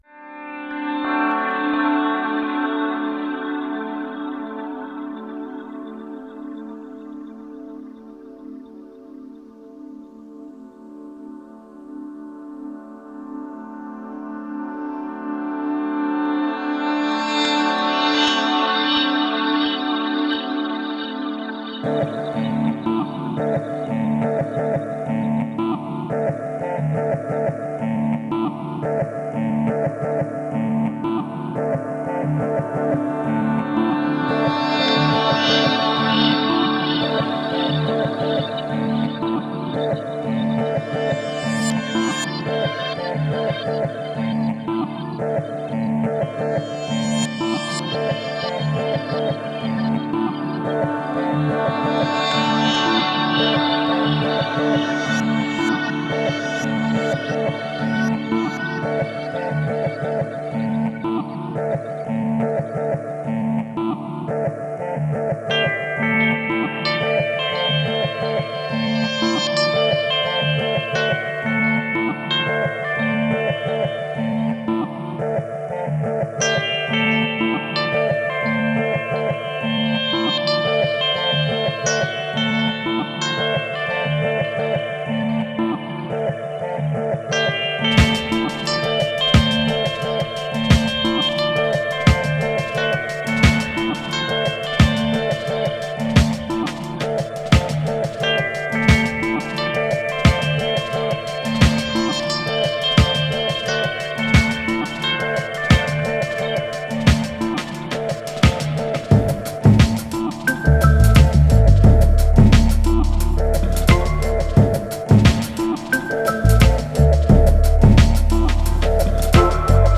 Genre: Dub, Downtempo, Ambient.